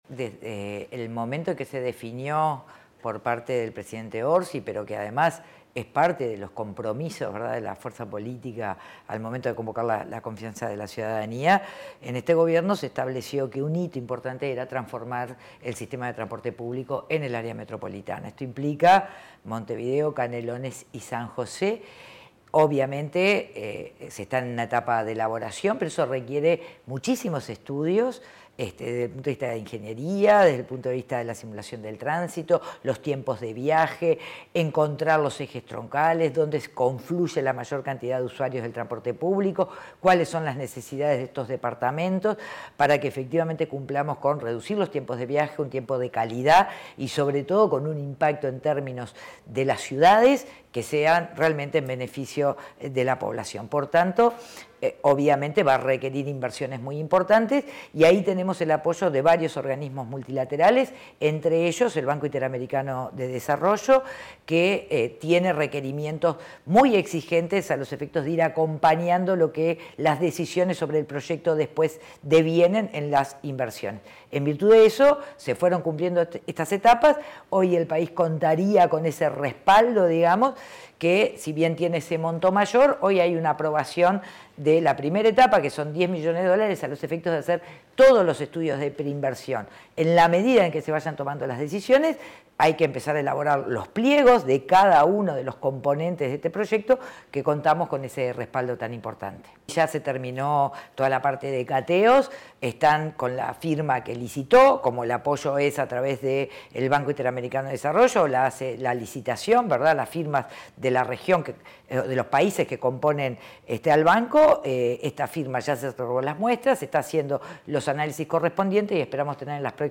Declaraciones de la ministra de Transporte y Obras Públicas, Lucía Etcheverry